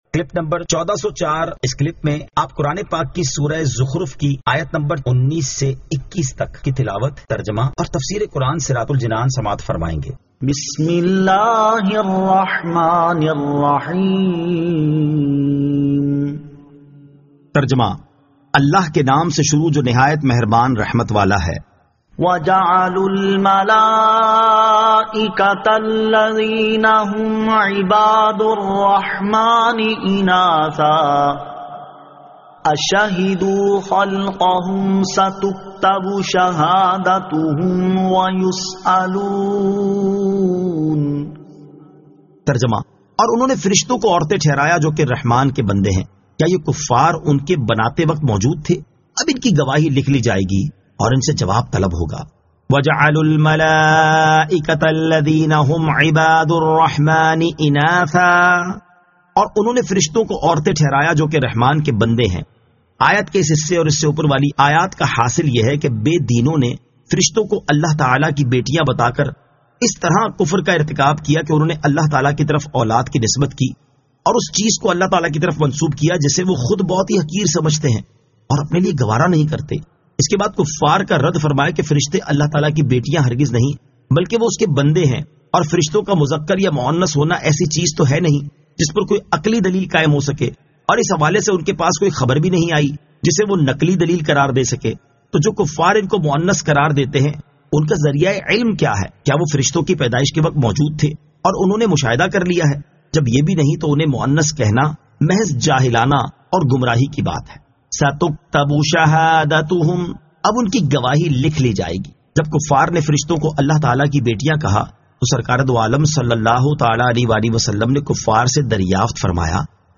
Surah Az-Zukhruf 19 To 21 Tilawat , Tarjama , Tafseer